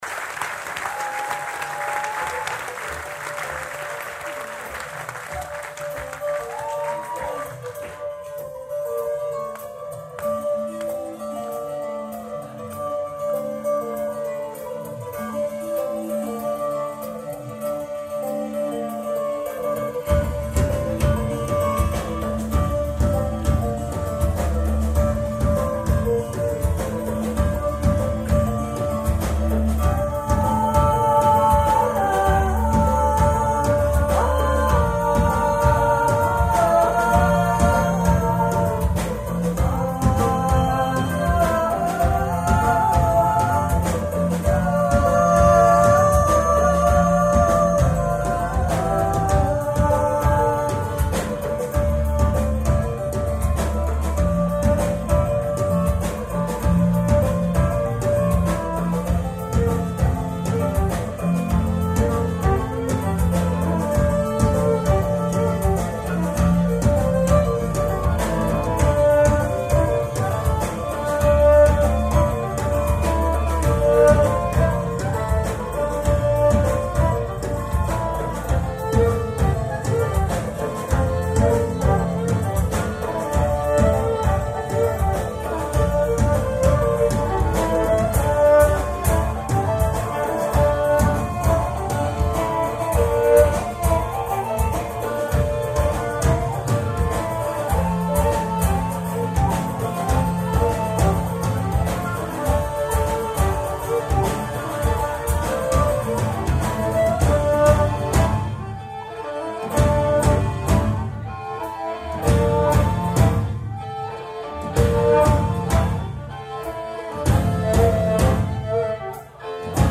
whistles and vocals
guitars and vocals
fiddle
keyboard and vocals
whistles, mandolin, banjo, guitar
full-force bands that have to be heard to be believed
a reel